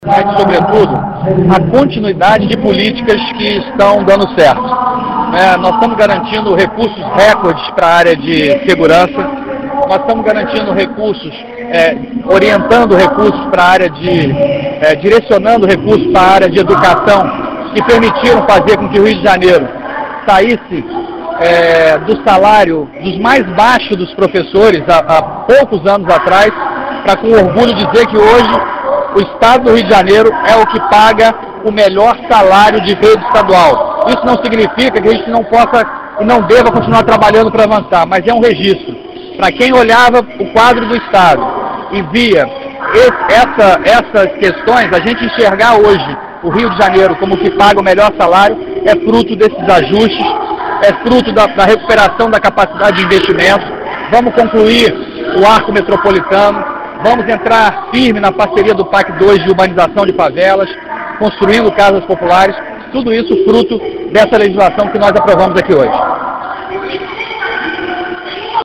ÁUDIO – André Corrêa comenta ajuste fiscal do estado e articulação na ALERJ